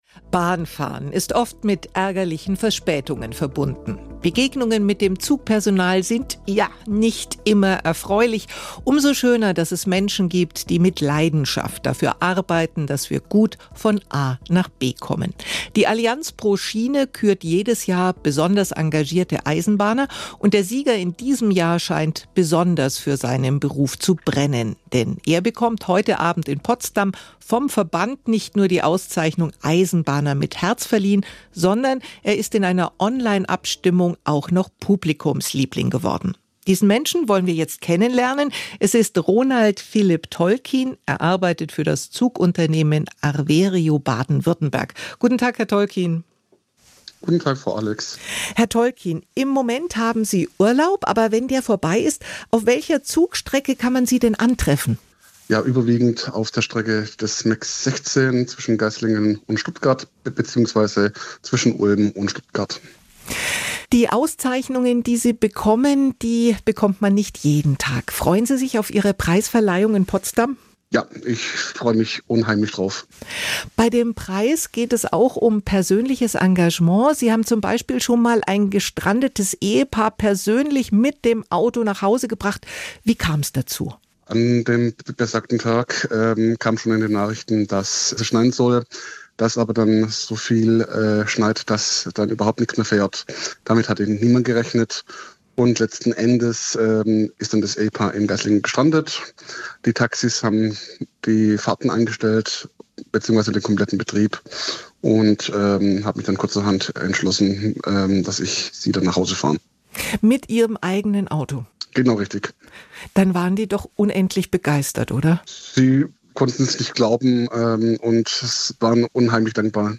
Die besten Interviews aus dem Radioprogramm SWR Aktuell: jederzeit zum Nachhören und als Podcast im Abo